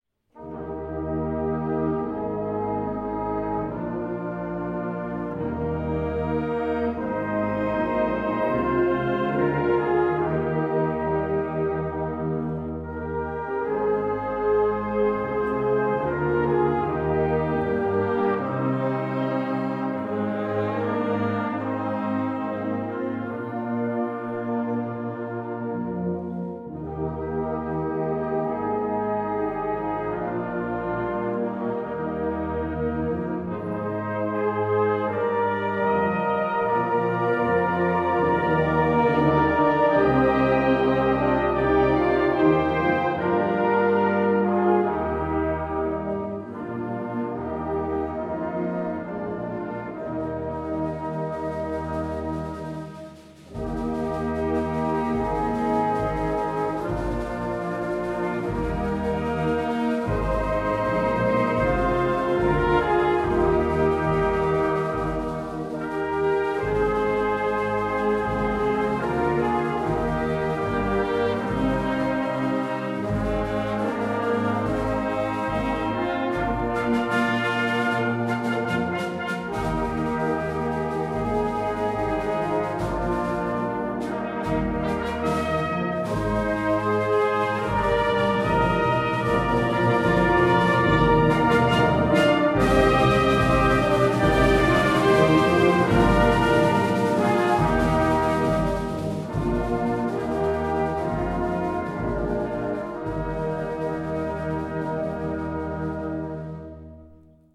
Gattung: Choral
Besetzung: Blasorchester
Leicht zu spielen mit großer Wirkung!